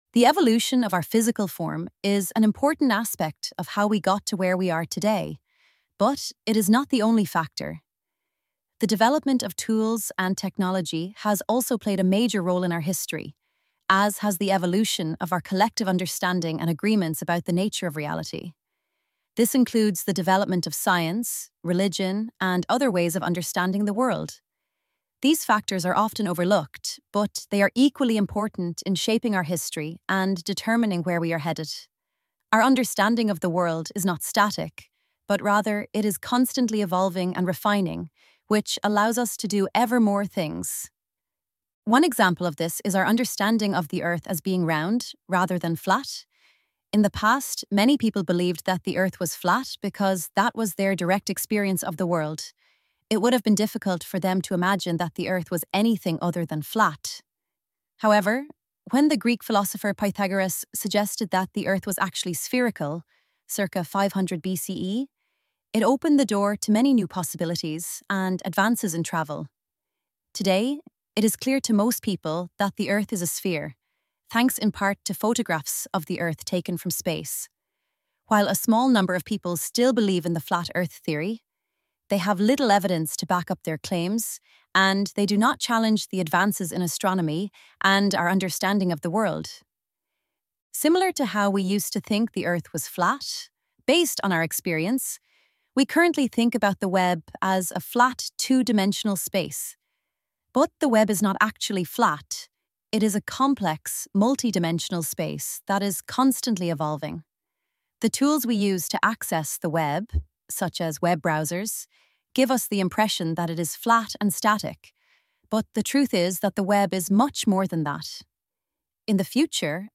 elevenlabs_the_preface-mePJvKNw40t15G6E.mp3